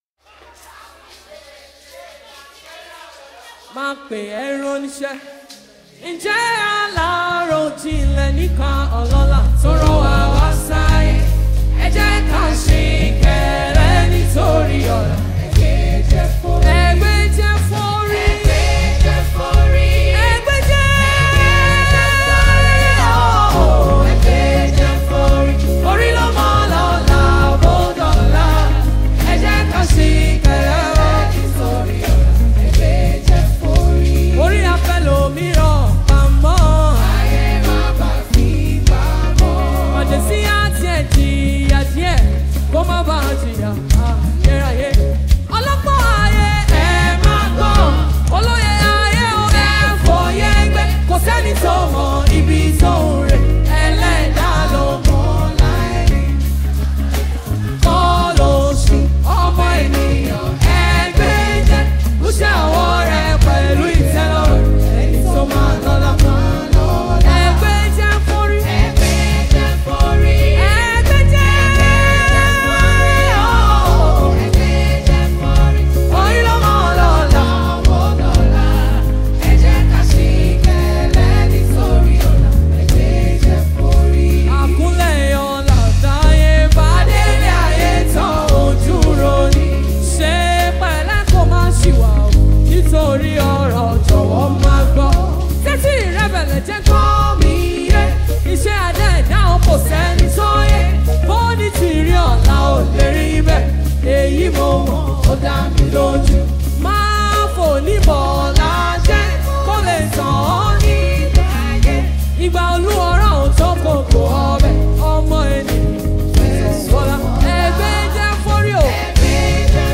Gospel, Yoruba Islamic Music 0
Yoruba Fuji track
Nigerian Yoruba gospel songs
Yoruba Worship Song